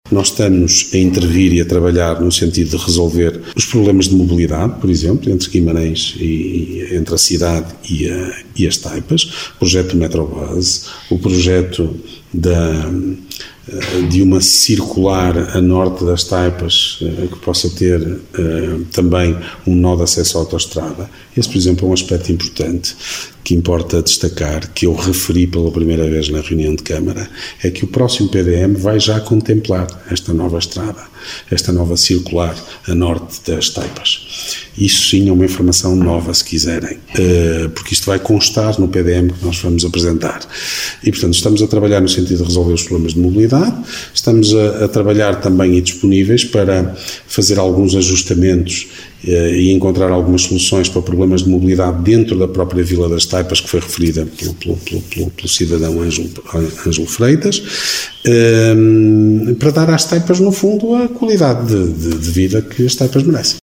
Declarações de Ricardo Araújo, presidente da Câmara Municipal de Guimarães.